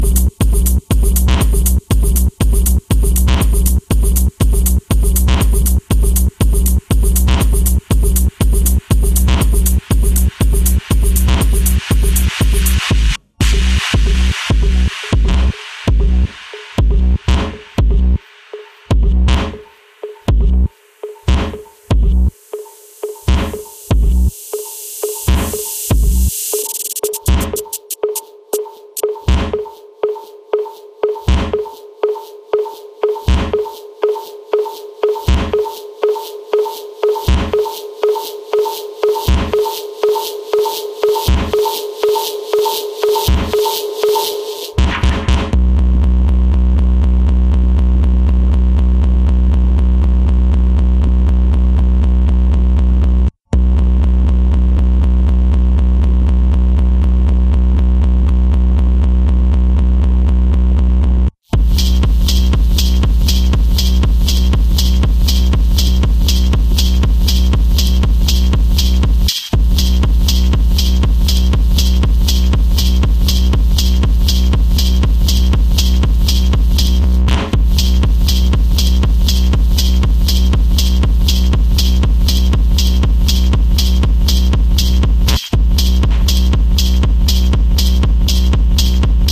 Storming Techno tracks
if you like you techno banging
Techno 12inch